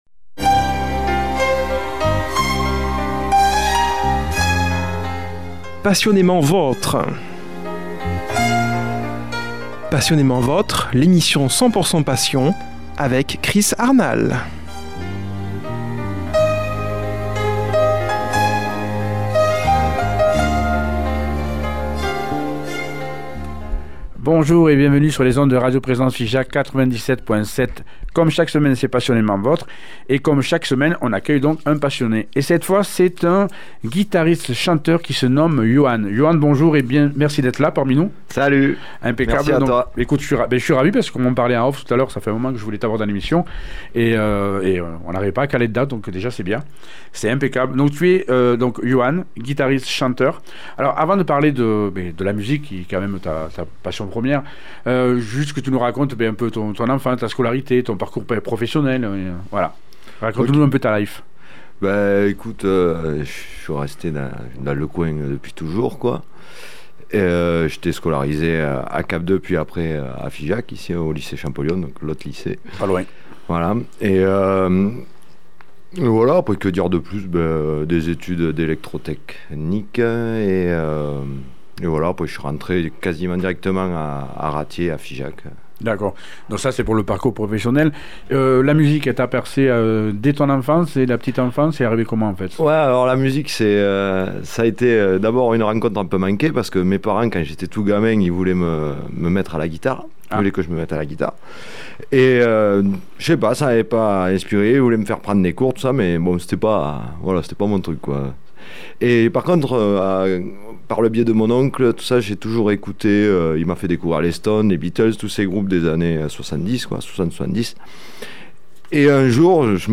ambiance blues
au studio